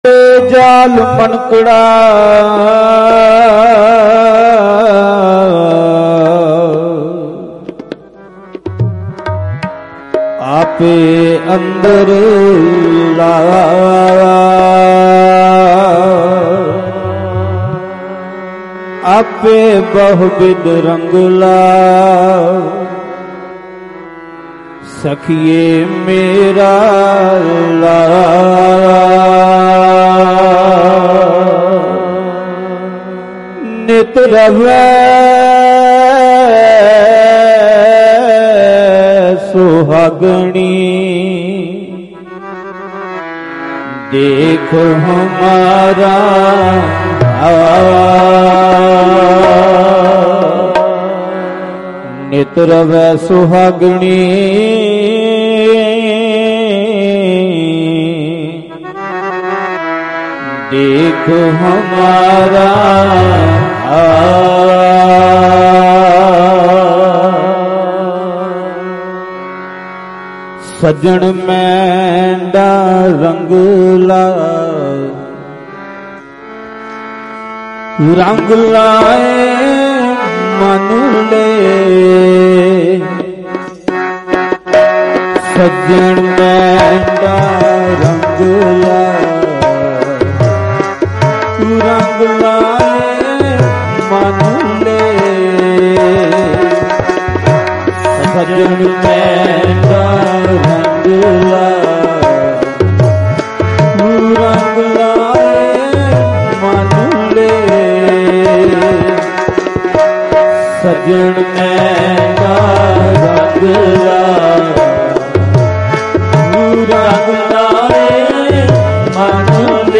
Live Gurmat Samagam Khojkipur Adampur, Jalandhar 10 Nov 2025